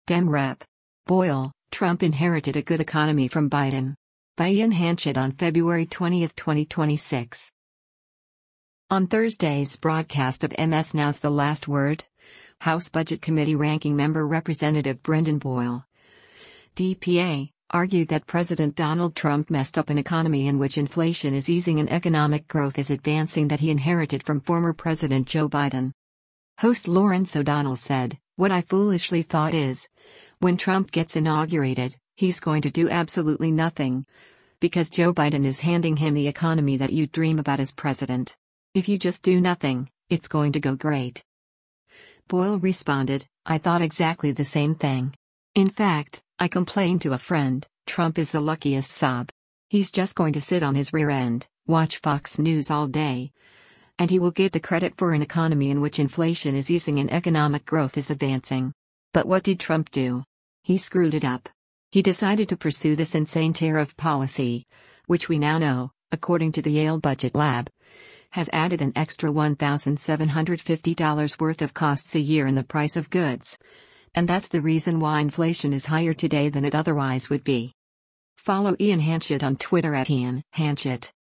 On Thursday’s broadcast of MS NOW’s “The Last Word,” House Budget Committee Ranking Member Rep. Brendan Boyle (D-PA) argued that President Donald Trump messed up “an economy in which inflation is easing and economic growth is advancing” that he inherited from former President Joe Biden.